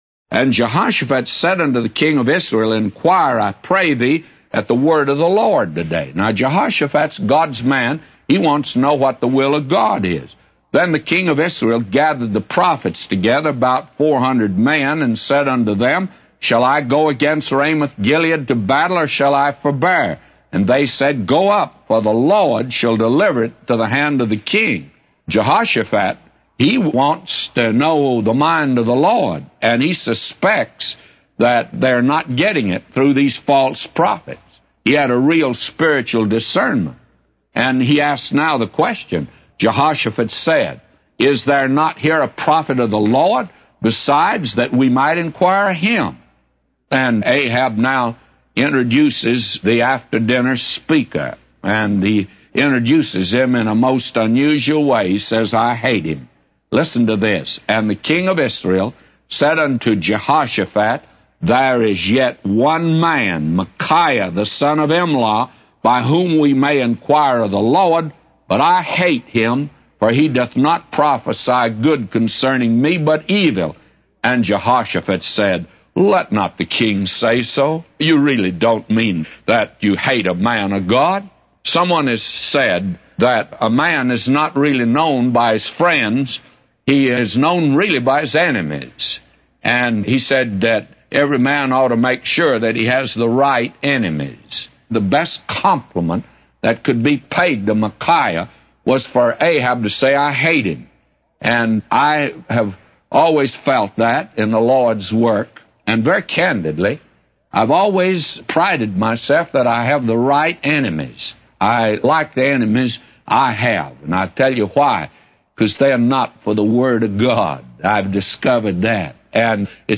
A Commentary By J Vernon MCgee For 1 Kings 22:5-999